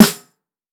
Major Snare.wav